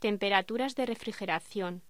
Locución: Temperaturas de refrigeración
voz